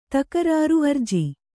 ♪ takarāru arji